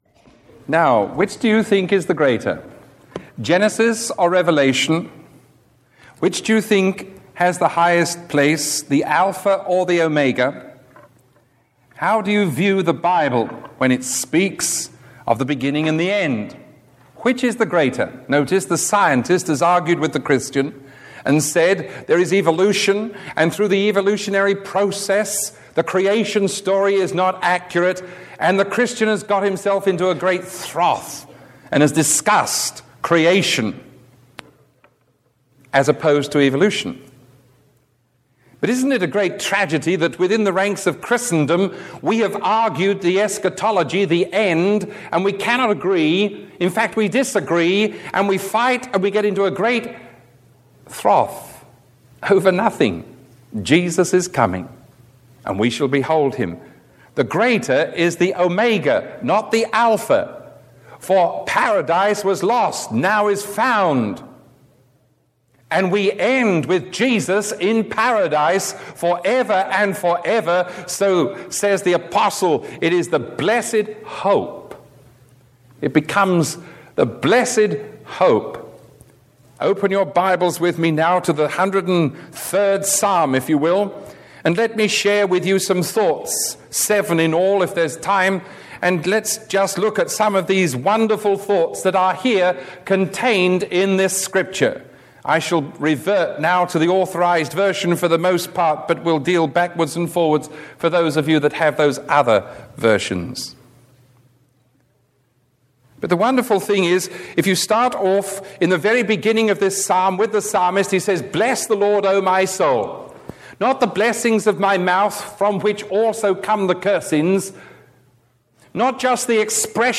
Sermon 0944B recorded on October 21